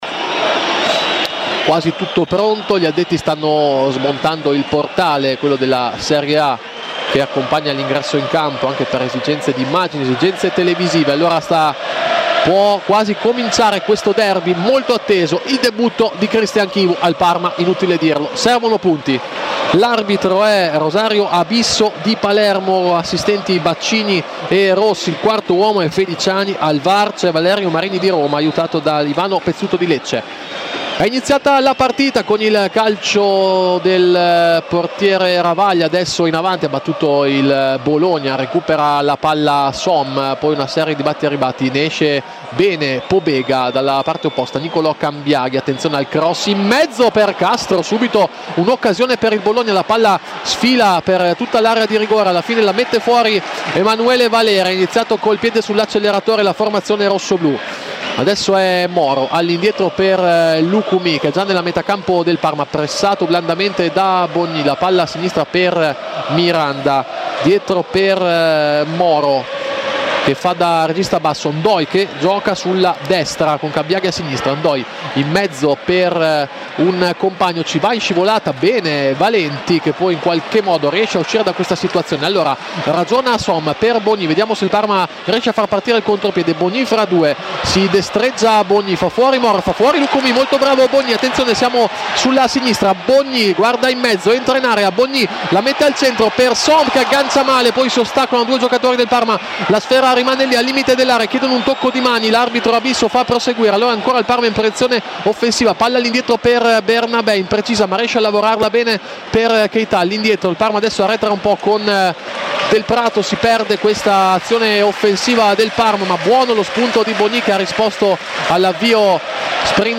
Radiocronache Parma Calcio Parma - Bologna 1° tempo - 22 febbraio 2025 Feb 22 2025 | 00:47:39 1x 00:00 / 00:47:39 Subscribe Share RSS Feed Share Link Embed